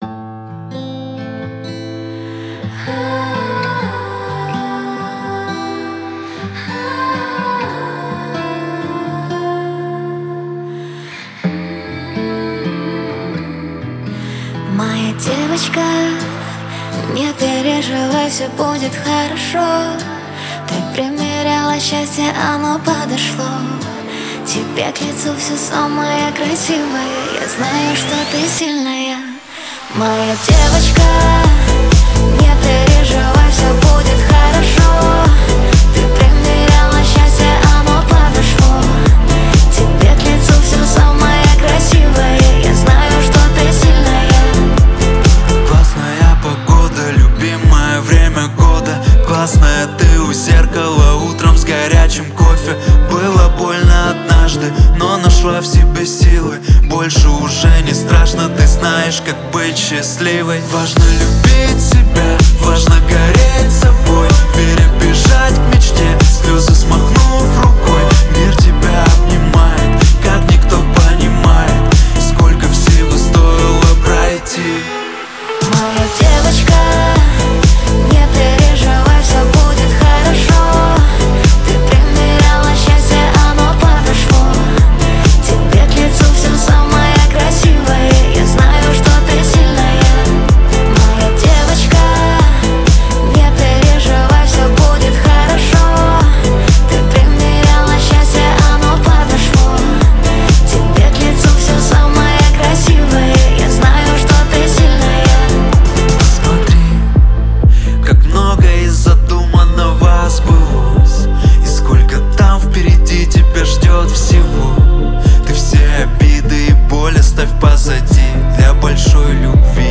Драм-н-басс